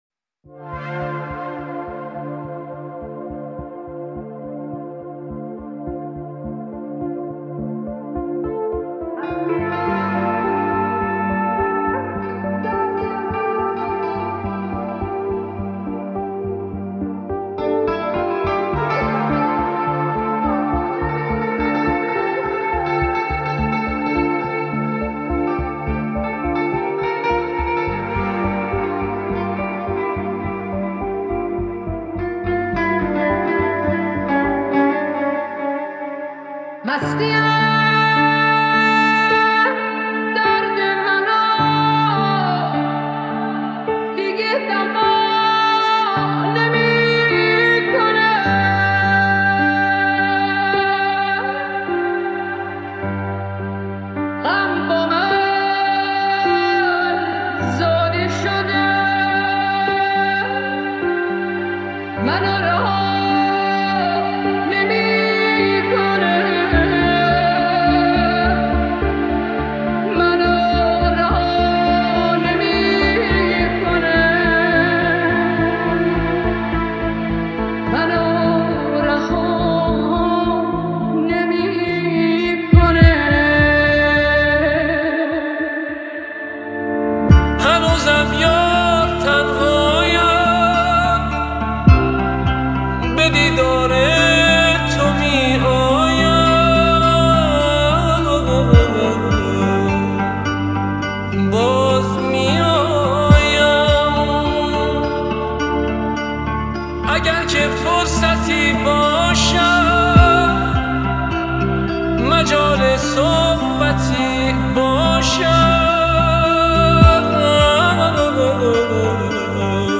ریمیکس پاپ